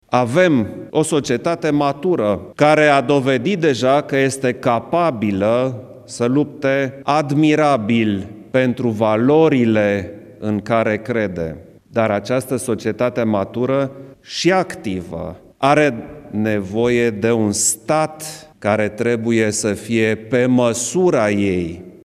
Șeful statului făcut precizările marți, 23 ianuarie 2018, pentru jurnaliști, după întâlnirea cu diplomații străini acreditați în București și reuniți la Palatul Cotroceni la invitația președintelui țării.